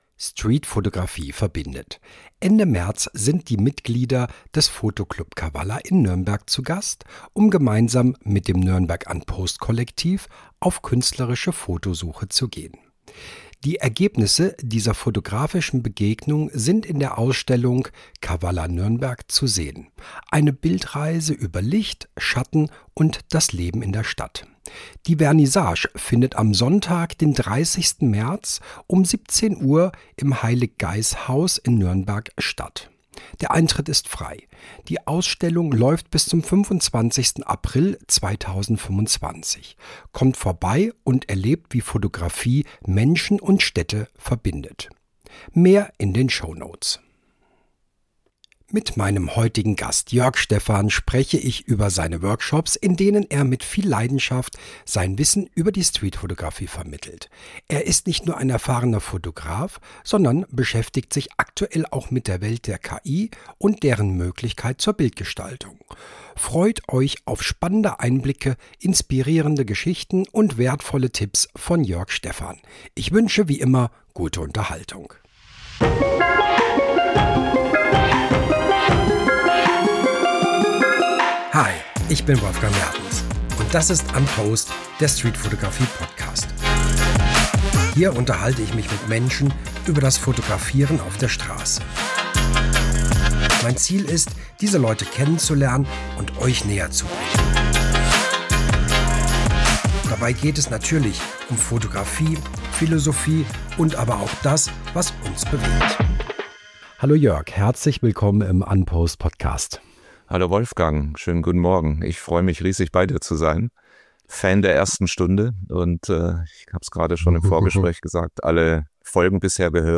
Hier unterhalte ich mich mit Menschen über das Fotografieren auf der Strasse.